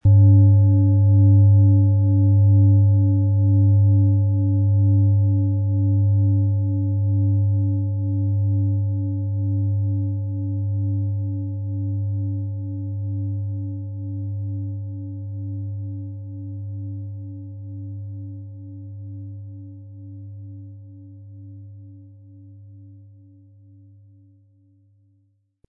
Tibetische Bauch-Gelenk-Universal- und Kopf-Klangschale
Der passende Klöppel ist kostenlos dabei, der Schlegel lässt die Klangschale harmonisch und wohltuend anklingen.
MaterialBronze